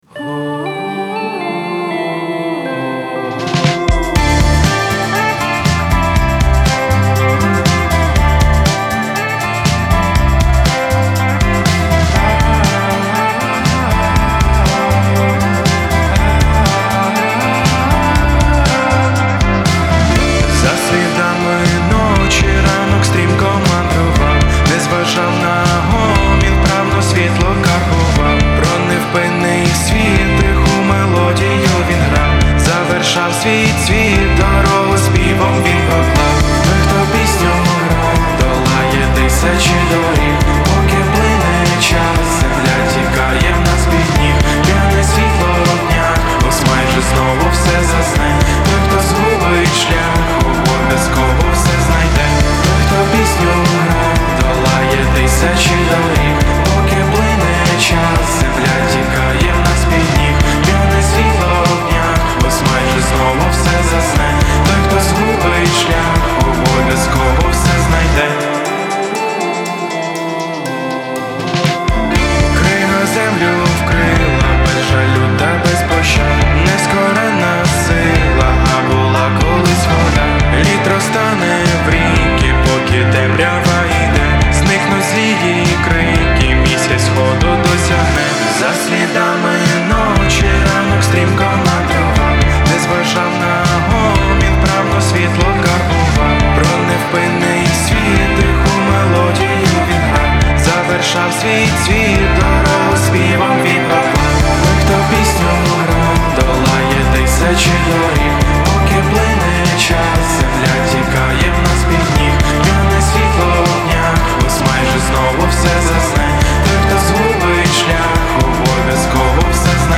• Жанр: Музика, Rock